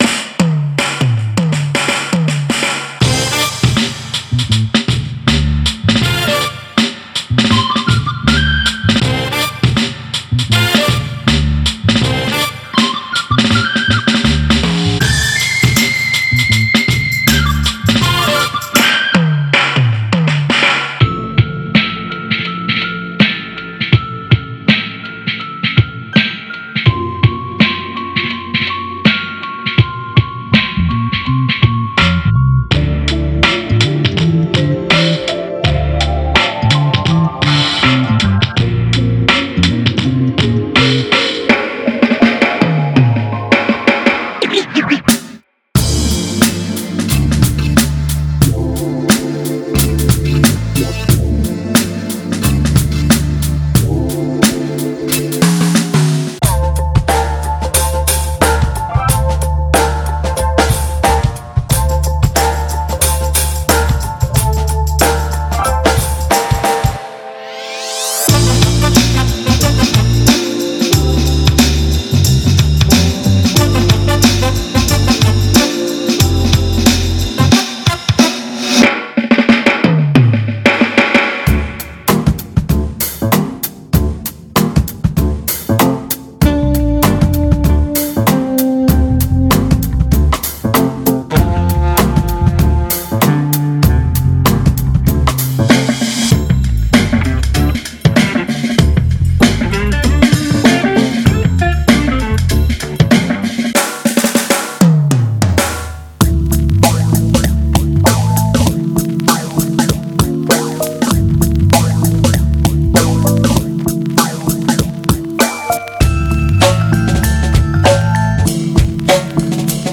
Genre:Hip Hop
テンポは61から146 BPMまで幅広く、あなたのプロダクションに確かなグルーヴを注入する準備が整っています。
収録内容には、シャッフル、ルーズグルーヴ、タイトなフィル、ゆったりとしたリズム、ハイエナジーなブレイクが含まれています。
デモで聞こえるメロディ要素は、あくまでデモ用として含まれています。
69 Live Drum Breaks